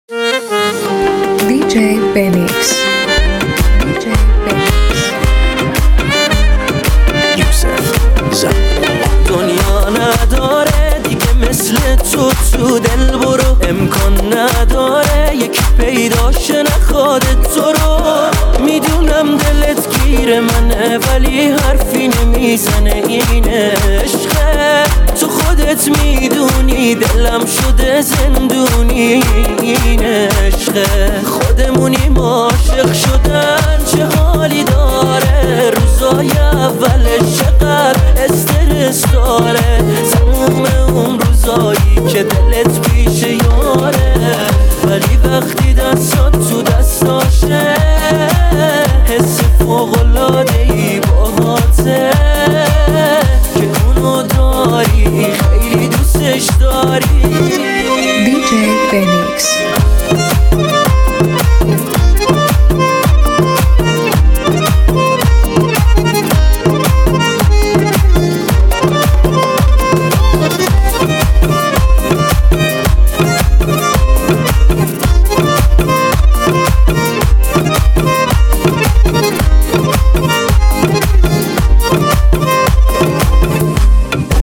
این ریمیکس پرانرژی
با صدای دلنشین و احساسی‌اش